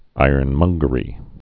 (īərn-mŭnggə-rē, -mŏng-)